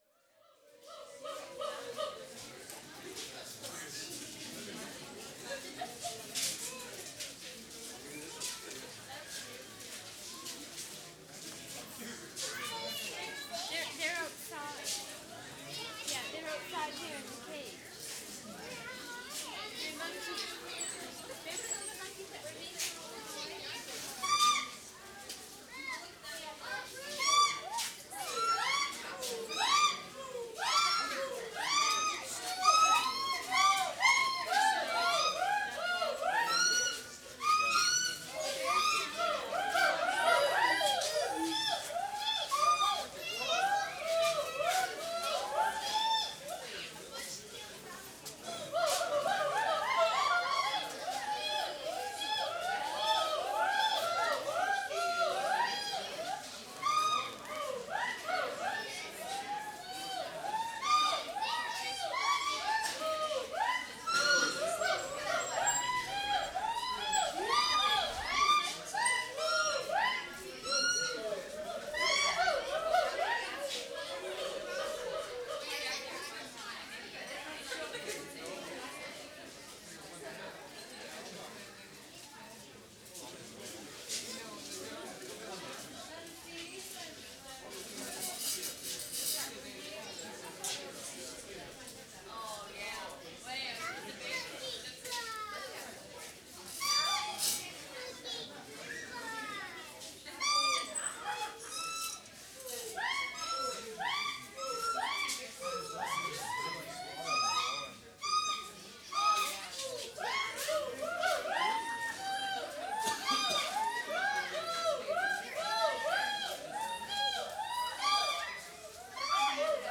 WORLD SOUNDSCAPE PROJECT TAPE LIBRARY
STANLEY PARK ZOO March 25, 1973
5. Gibbons again, from a partly enclosed, the monkey house.